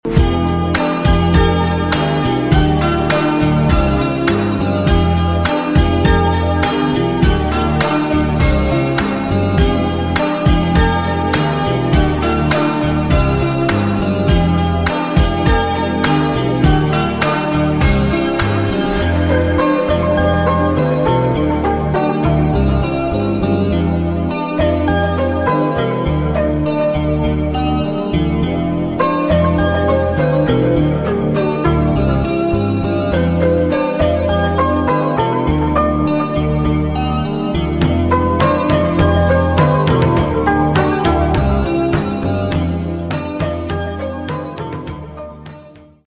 8300 rpm med manuell kasse sound effects free download